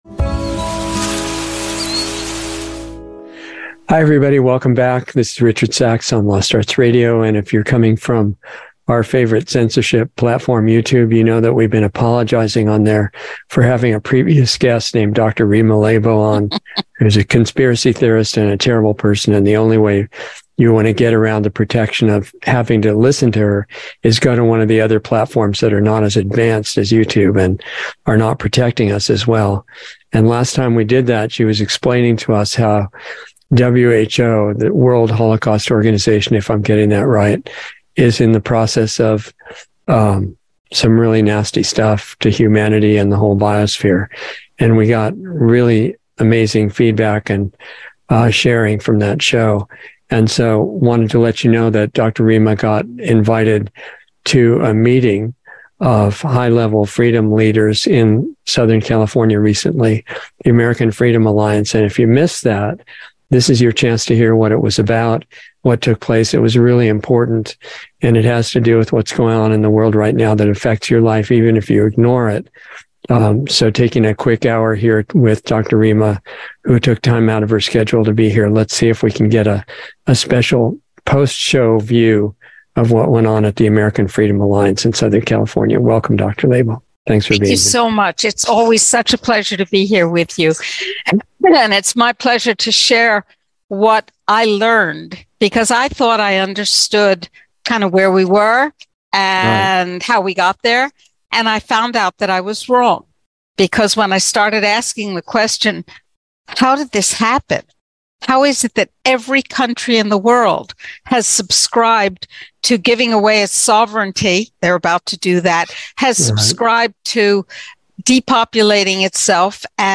Lost Arts Radio Show on Sunday 5/7/23